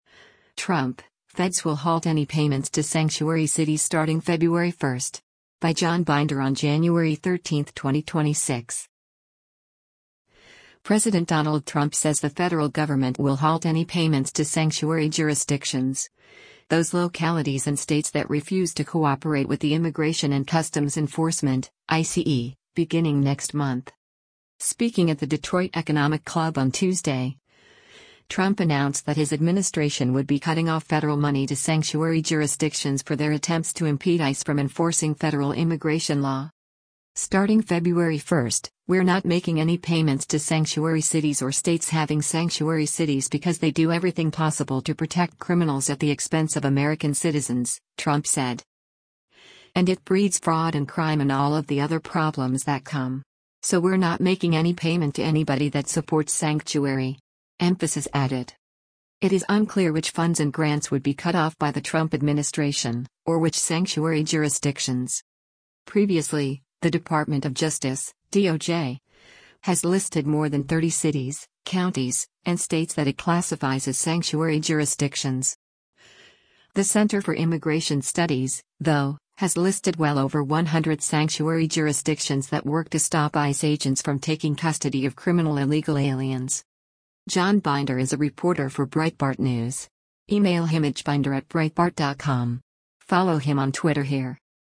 Speaking at the Detroit Economic Club on Tuesday, Trump announced that his administration would be cutting off federal money to sanctuary jurisdictions for their attempts to impede ICE from enforcing federal immigration law.